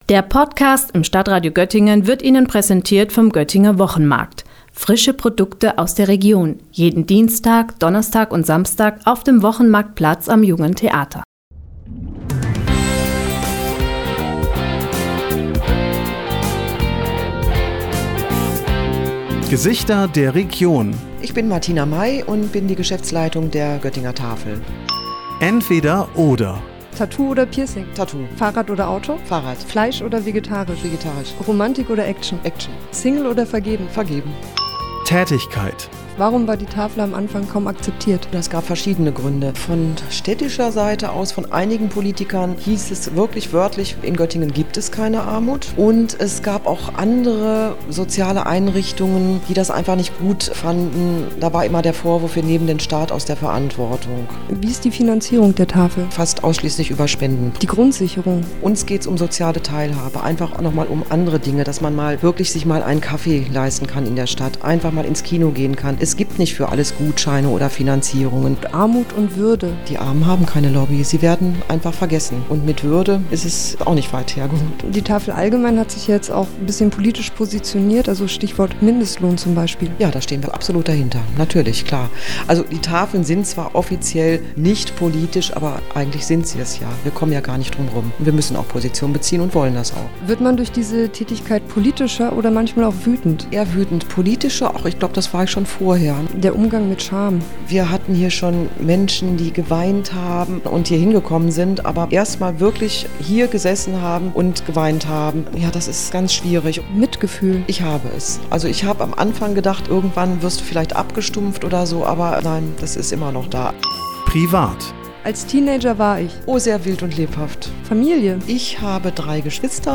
Musikbett